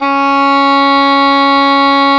WND OBOE C#4.wav